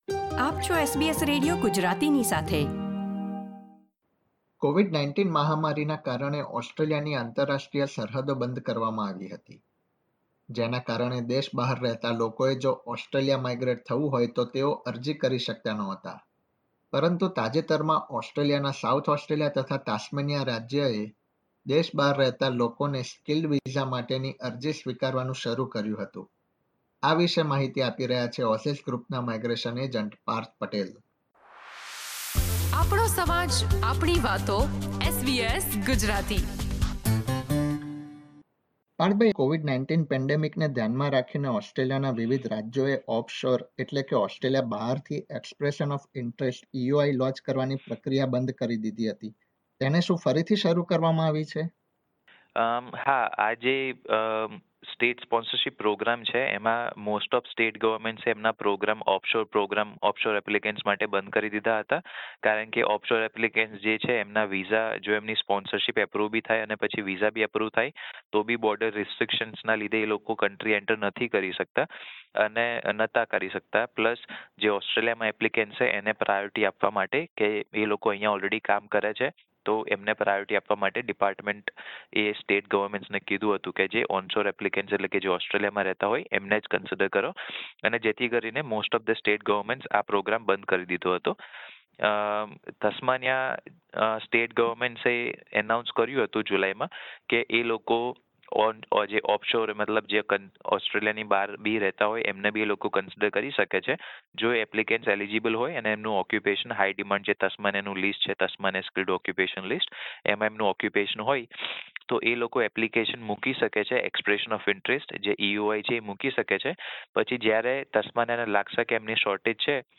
ઇન્ટરવ્યૂમાં આપવામાં આવેલી માહિતી સામાન્ય સંજોગો આધારિત છે.